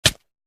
damage.wav